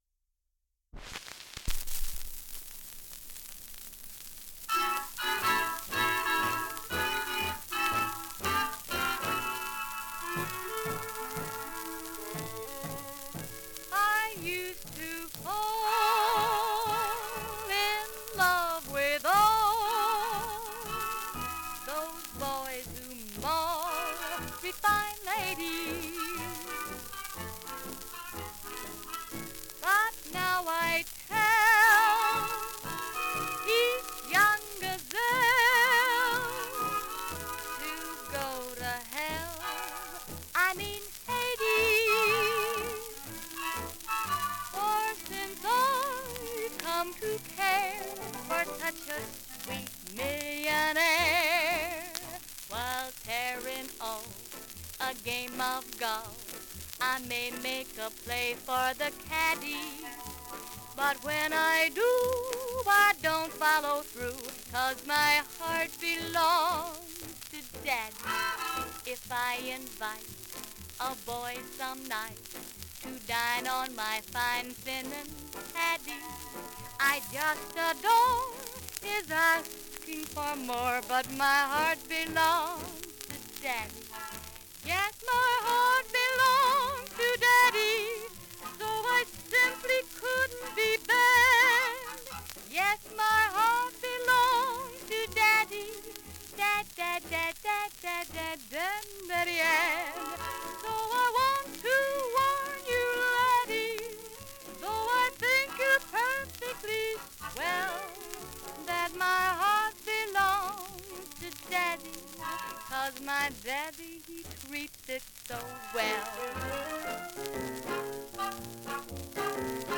Вокал с оркестром